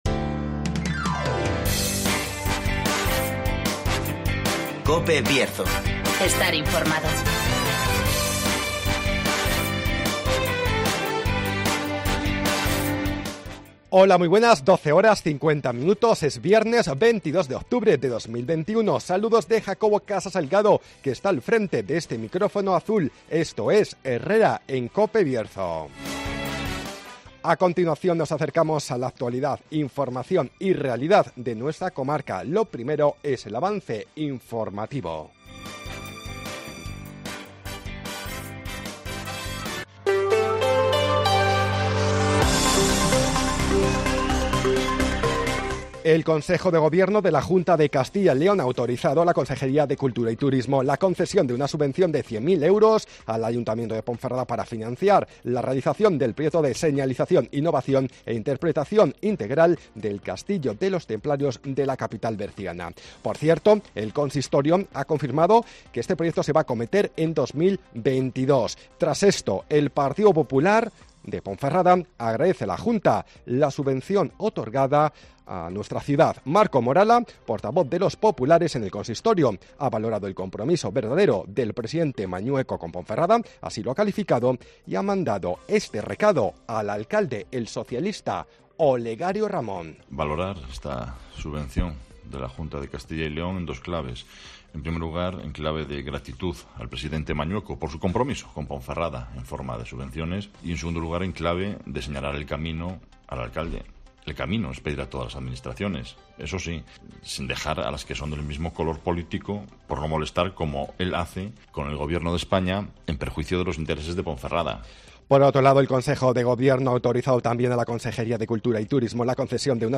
Avance informativo, El Tiempo y Agenda